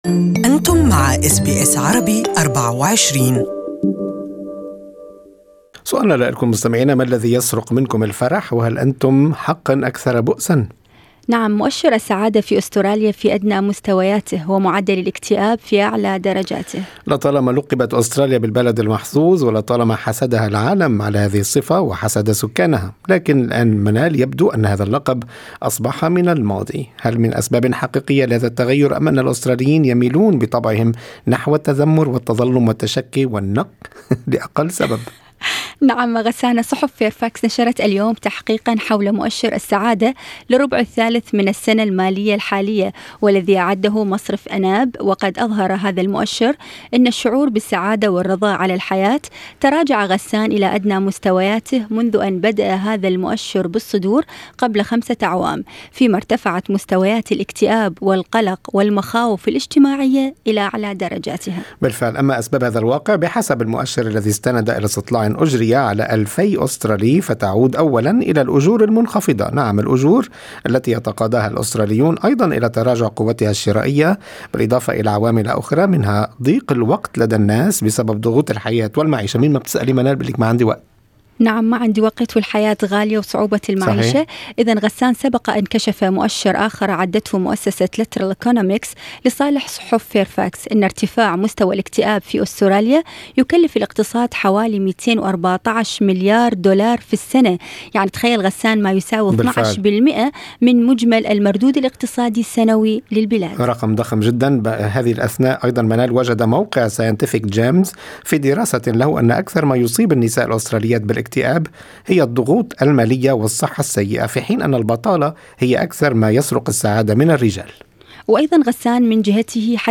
لنطلع على رأي الخبراء بالسعادة من خلال لقاء مع أخصائية الصحة النفسية